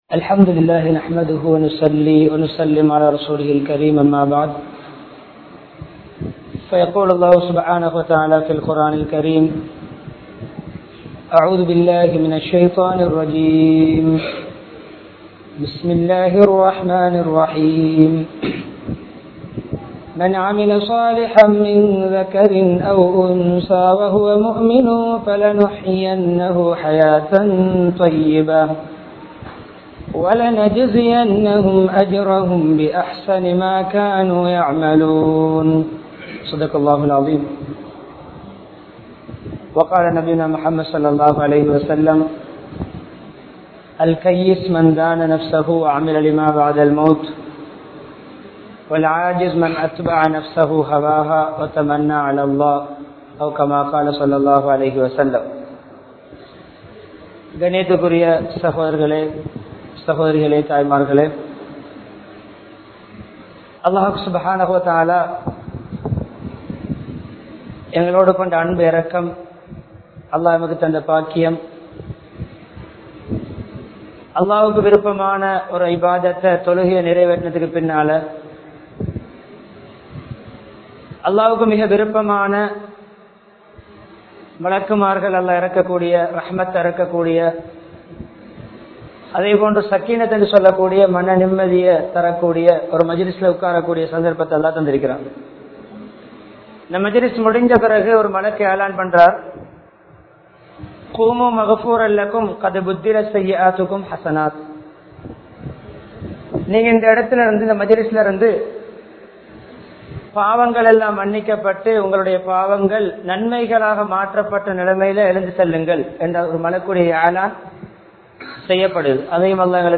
Pirachchinaihalukku Theervu Islamiya Vaalkai (பிரச்சினைகளுக்கு தீர்வு இஸ்லாமிய வாழ்க்கை) | Audio Bayans | All Ceylon Muslim Youth Community | Addalaichenai
Colombo 15, Mattakkuliya, Kandauda Jumua Masjidh